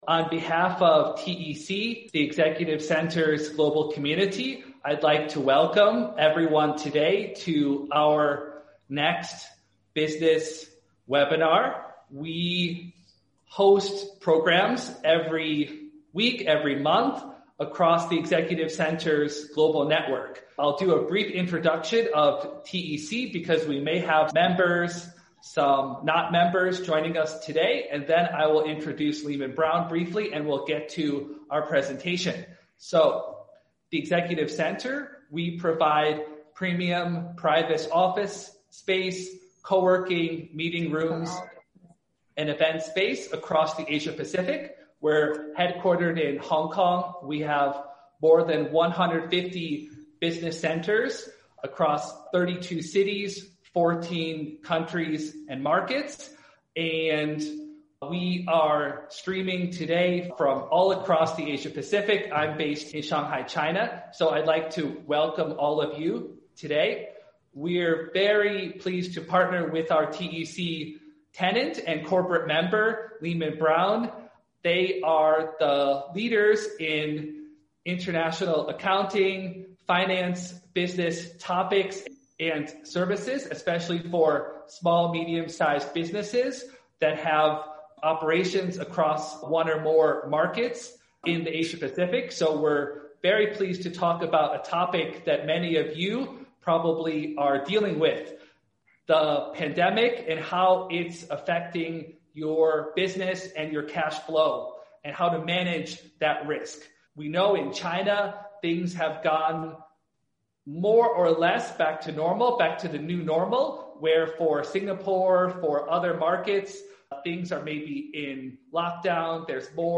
Managing Cash-flow to Manage Business Risks Webinar • Podcast